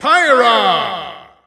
Announcer pronouncing Pyra's name in German.
Category:Pyra (SSBU) Category:Announcer calls (SSBU) You cannot overwrite this file.
Pyra_German_Announcer_SSBU.wav